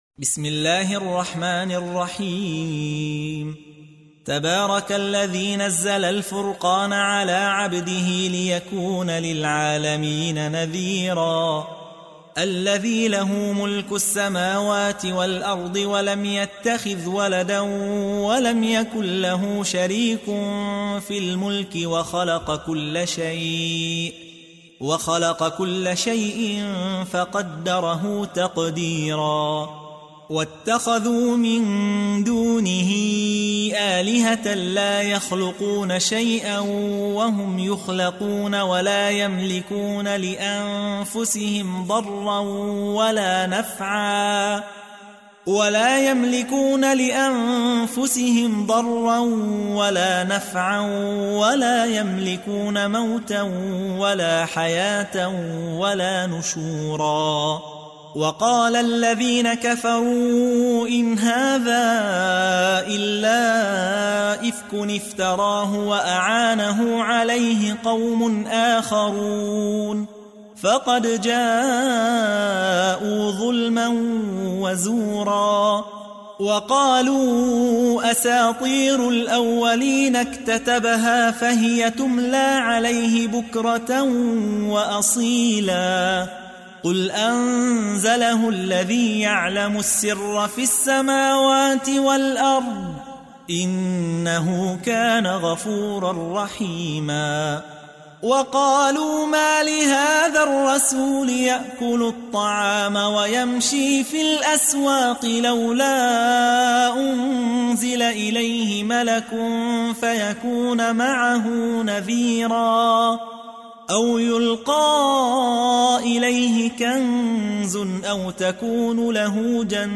تحميل : 25. سورة الفرقان / القارئ يحيى حوا / القرآن الكريم / موقع يا حسين